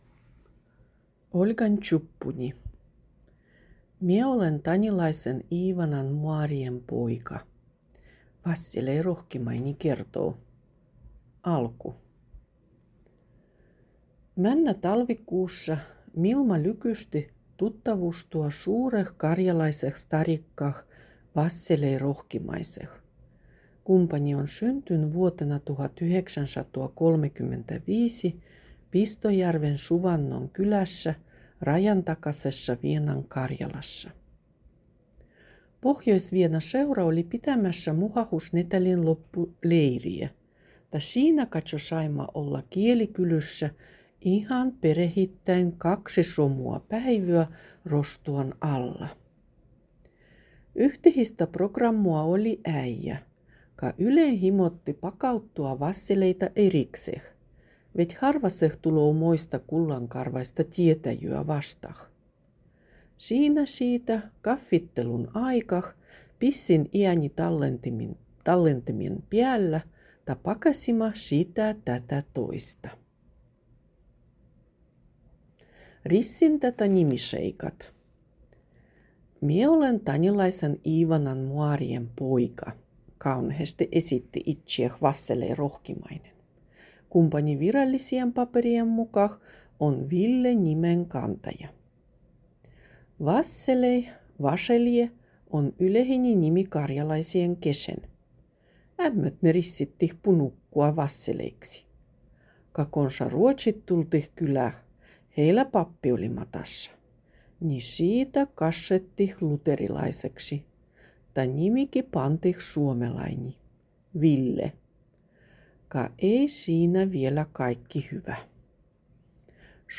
Šiinä šiitä kaffittelun aikah pissin iänitallentimen piällä ta pakasima šitä tätä toista.